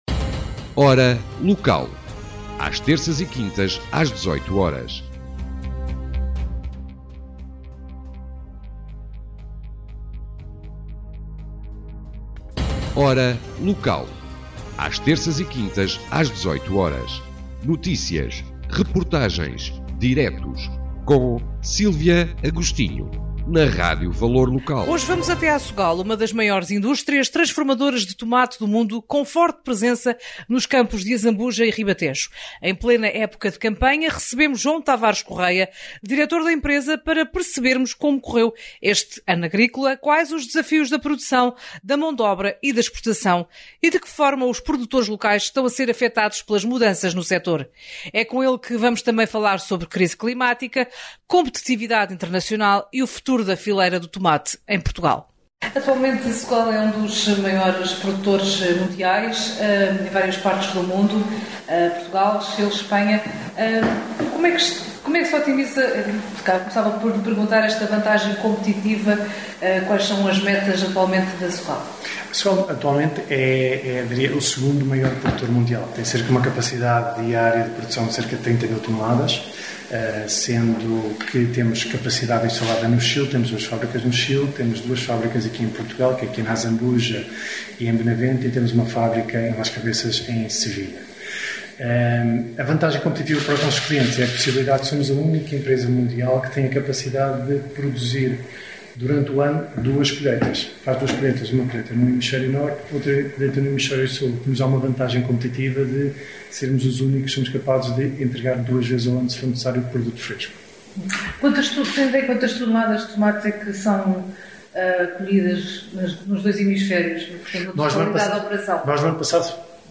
Em entrevista ao Valor Local